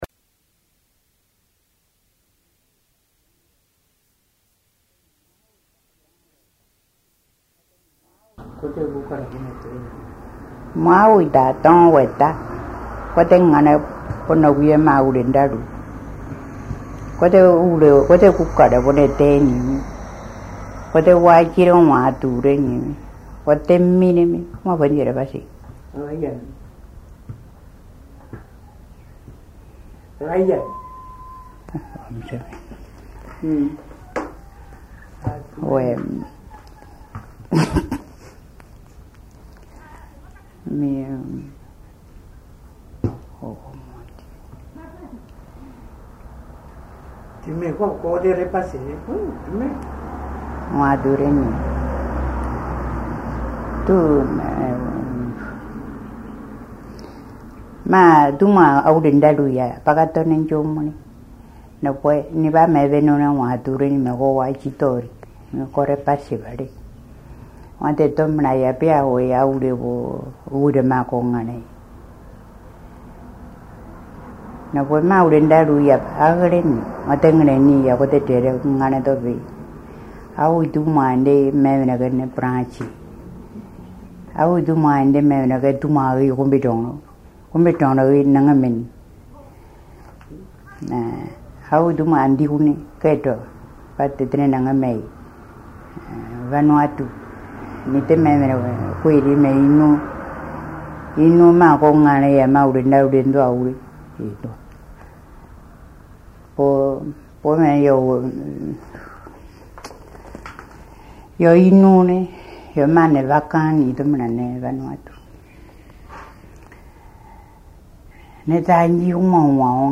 Documents joints Dialogue leçon 32 ( MP3 - 3.5 Mio ) Un message, un commentaire ?